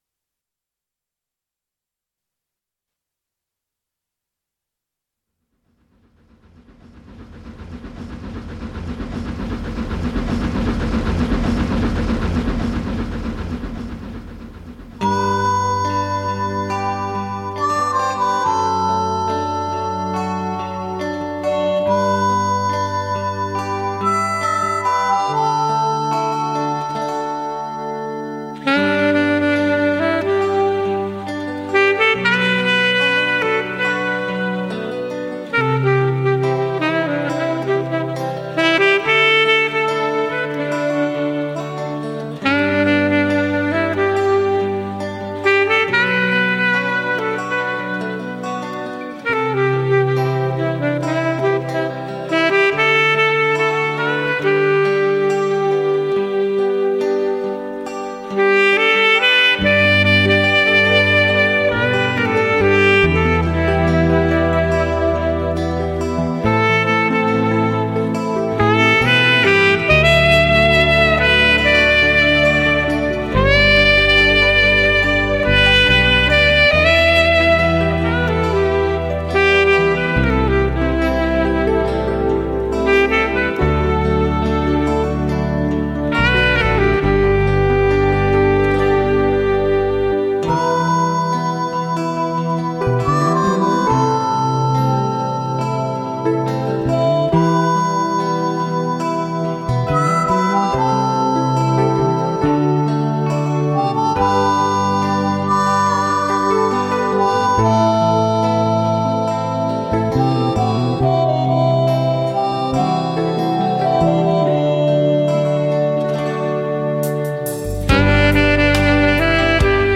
餐桌上的音乐调味瓶，为美食添味道，为生活添色彩，调剂你的心情，还有你的胃口，营造出最轻松惬意的用餐氛围。
单簧管独奏
萨克斯独奏
吉它伴奏
马头琴伴奏
小提琴伴奏
双簧管伴奏
长笛伴奏
小号伴奏
二胡伴奏
琵琶伴奏
古筝伴奏